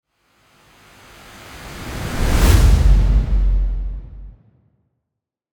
Efecto de sonido